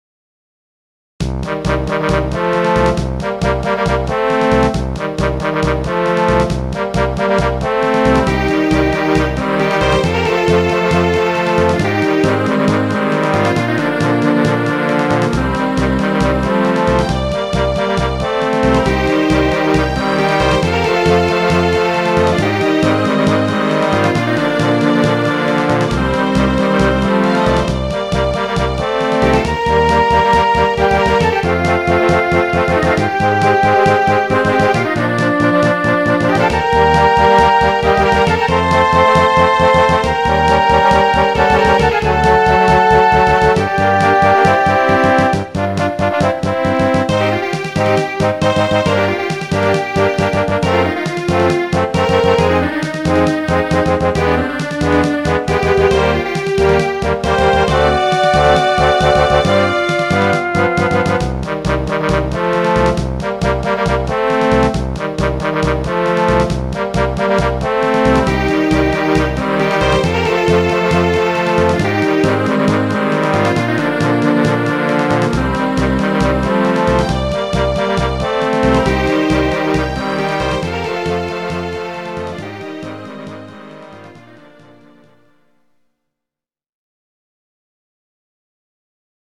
夕暮れっぽい背景にマッチした音楽。
GS音源。